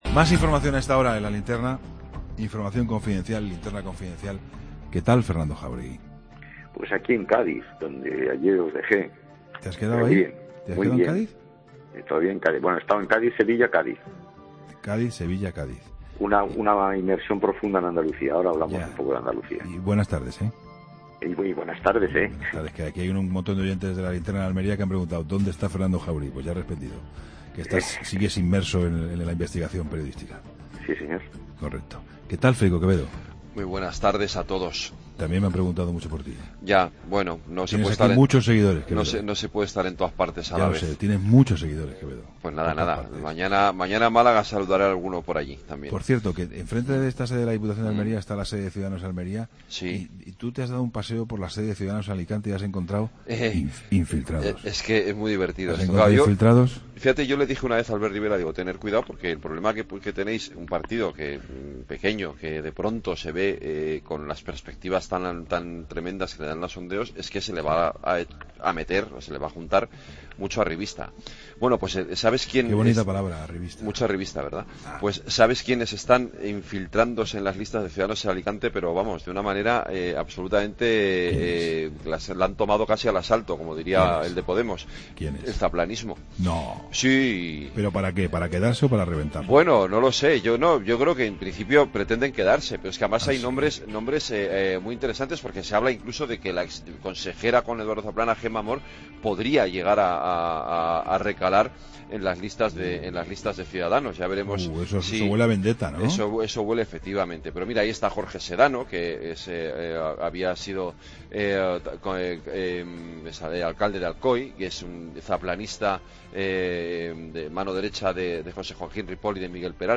Incluye entrevista a Teófila Martínez, alcaldesa de Cádiz.